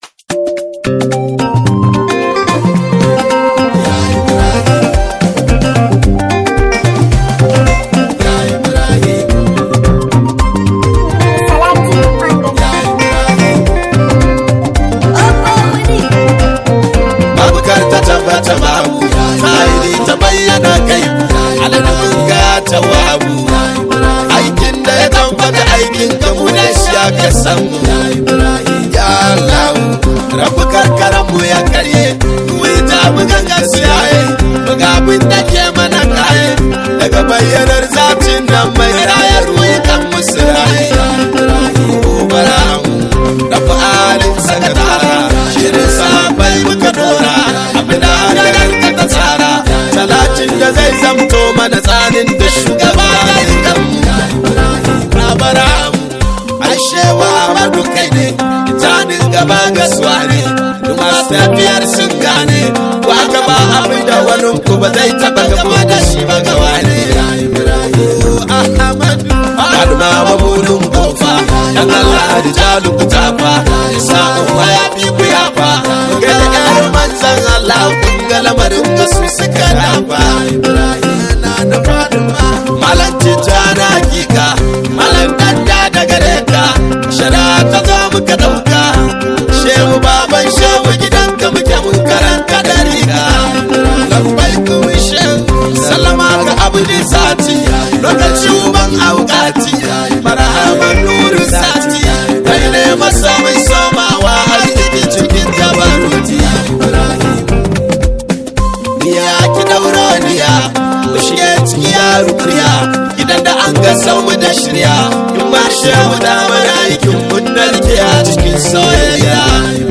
sabuwar waƙar yabo mai ratsa zuciya